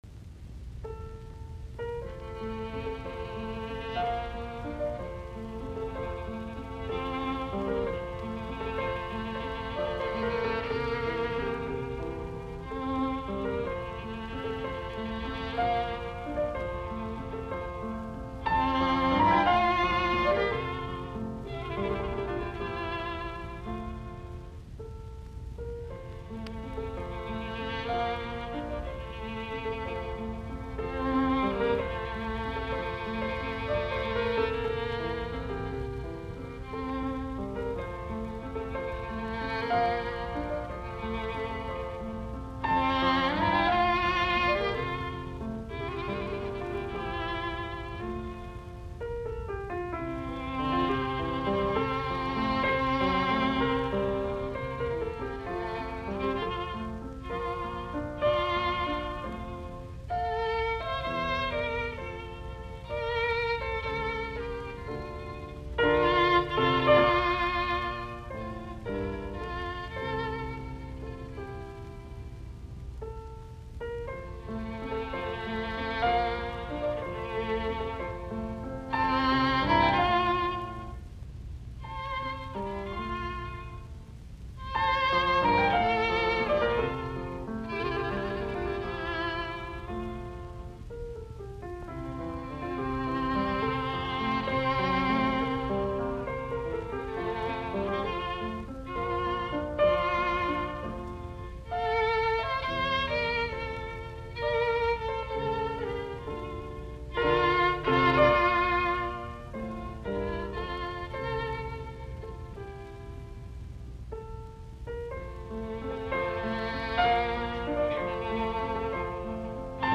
Soitinnus: Viulu, piano.